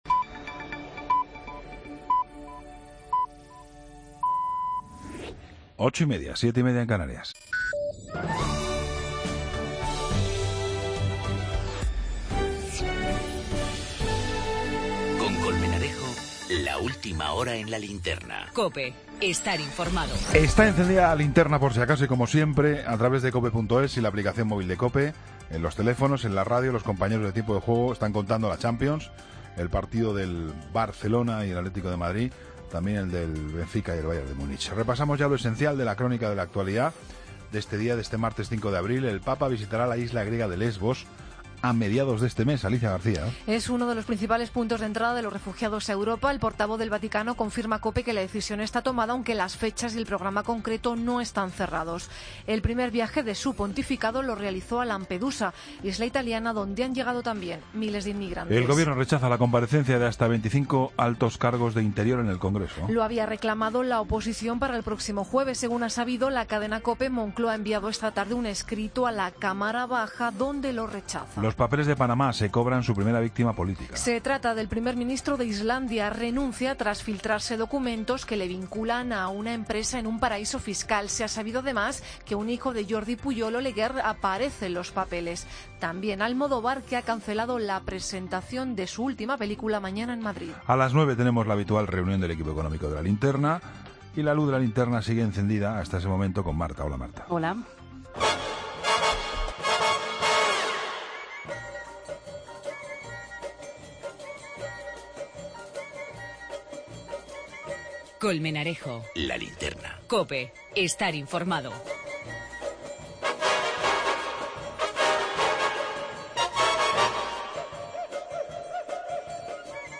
Entrevista al grupo de música Noise Nébula.